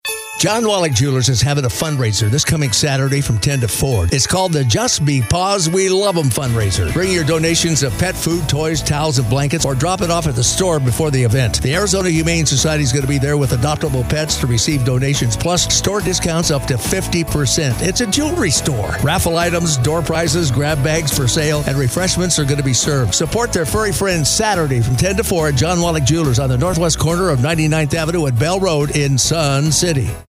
Play or Pause Radio Ad